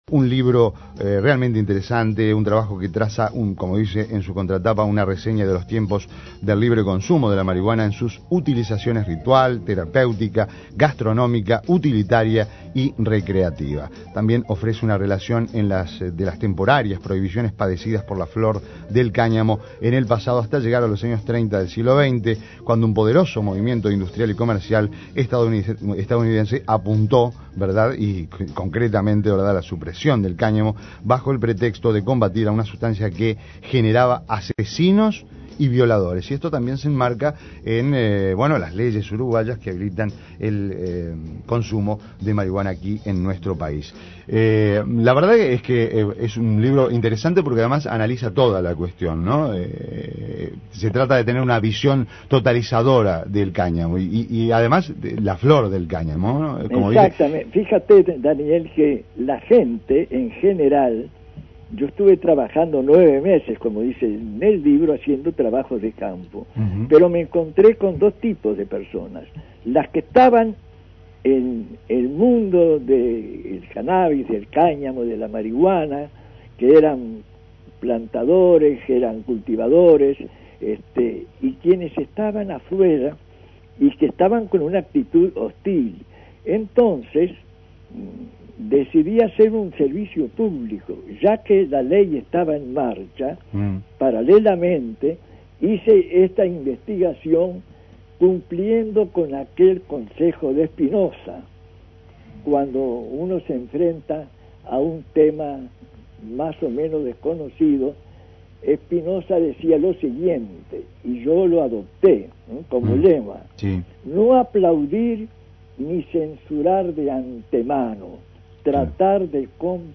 Entrevista a Daniel Vidart